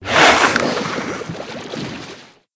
c_seasnake_atk2.wav